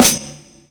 Tamb_live_snr.wav